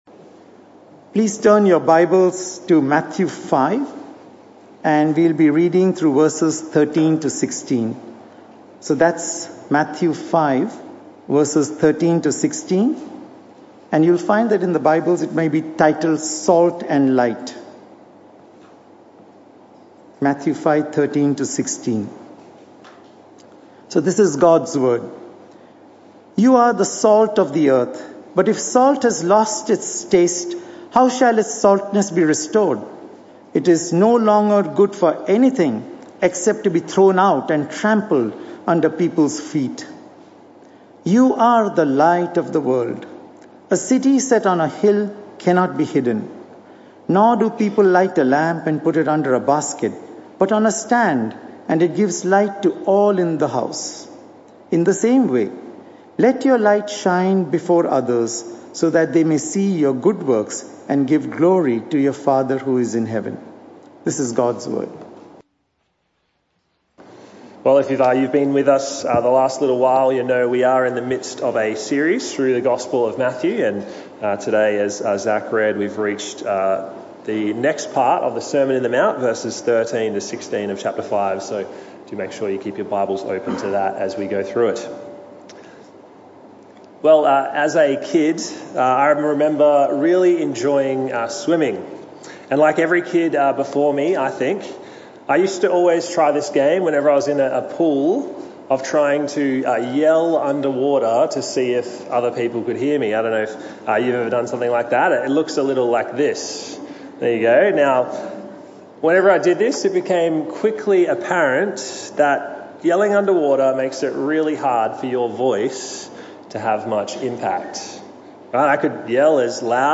This talk was part of the AM Service series entitled The Message Of Matthew.